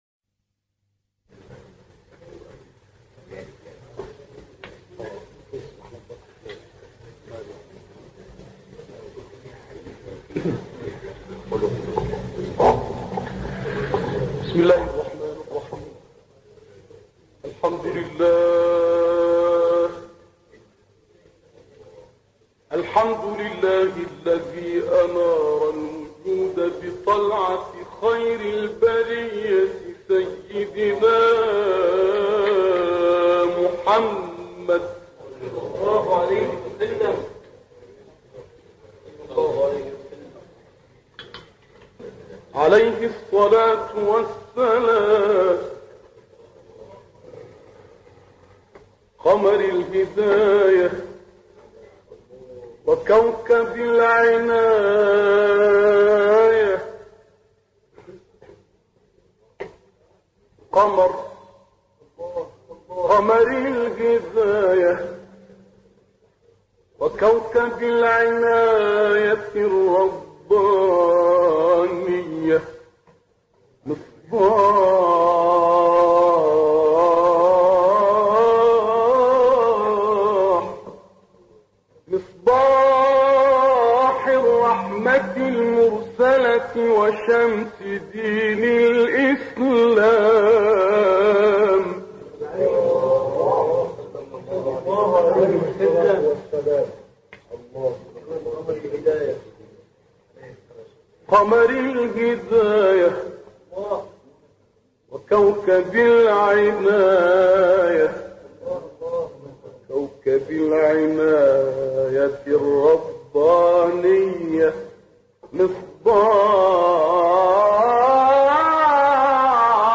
ابتهالی از «نقشبندی» به مناسبت سالروز درگذشت
به گزارش خبرگزاری بین المللی قرآن(ایکنا)، ابتهال شنیدنی «نبینا» با صوت سیدمحمد نقشبندی، قاری و مبتهل برجسته مصری در کانال تلگرامی اکبر القراء منتشر شده است.
این ابتهال در مسجد جامع اموی شهر حلب در کشور سوریه و در سال 1958 میلادی اجرا شده است و مدت زمان آن 105 دقیقه است.